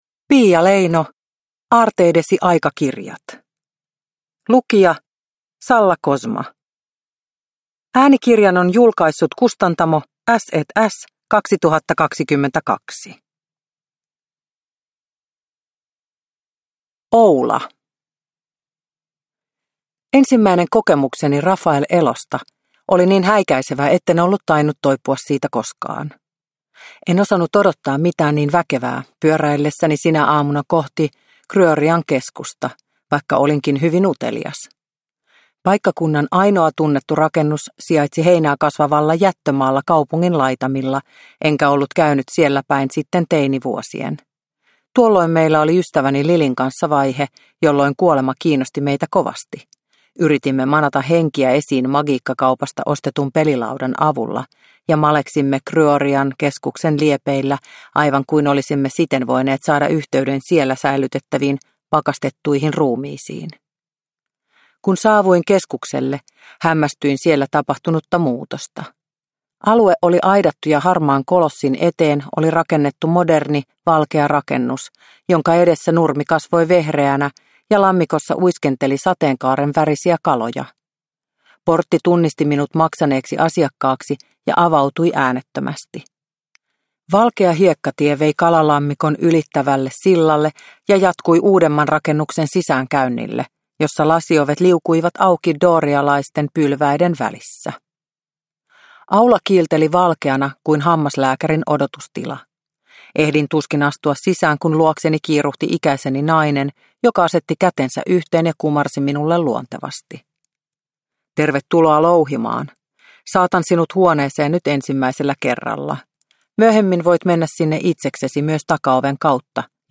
Aarteidesi aikakirjat – Ljudbok – Laddas ner